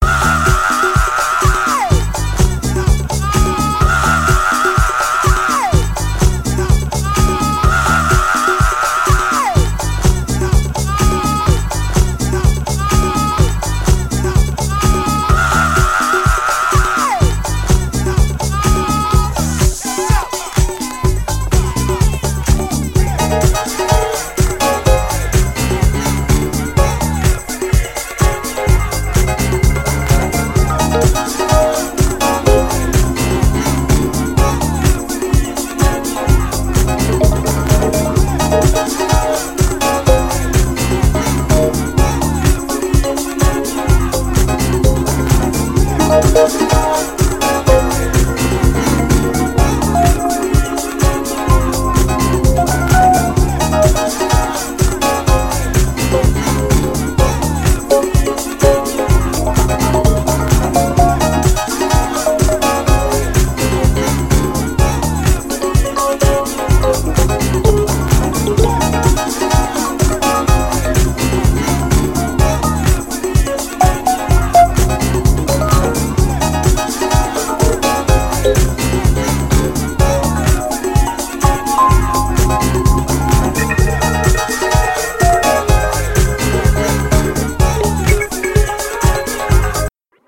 with added Hammond organ and Fender Rhodes